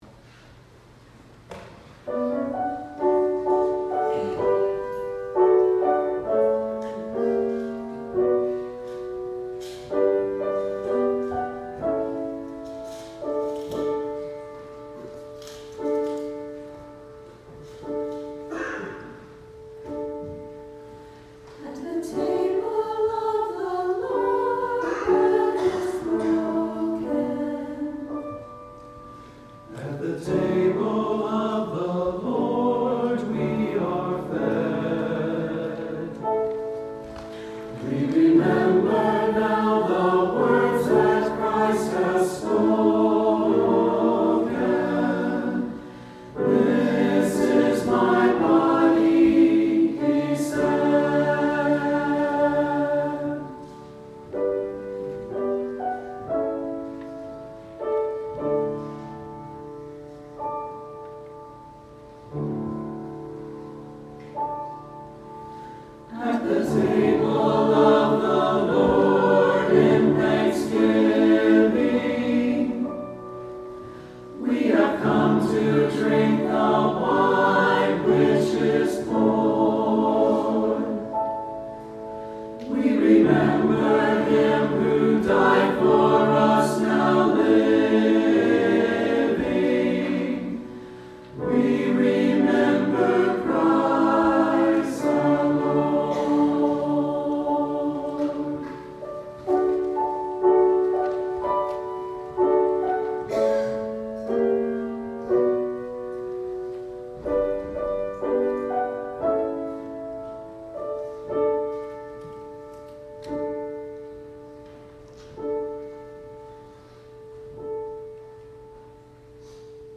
Offertory: Trinity Chancel Choir